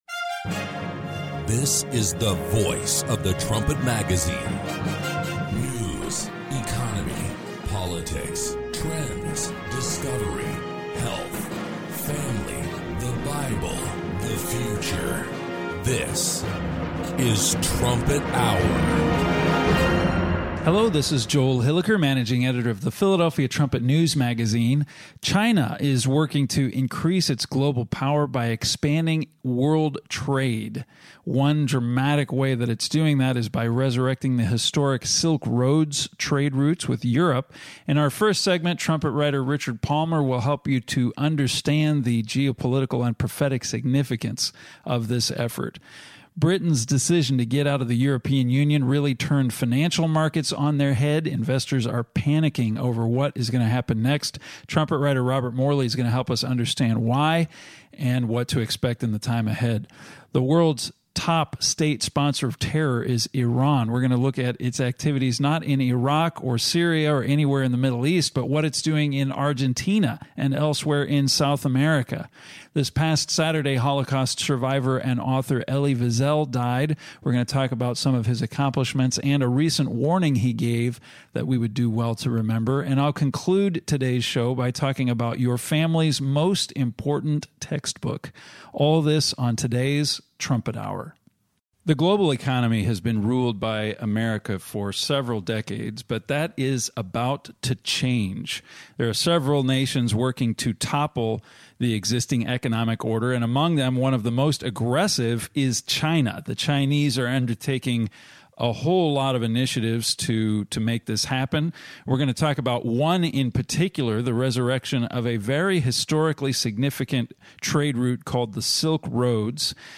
Join the discussion as Trumpet staff members compare recent news to Bible prophecy.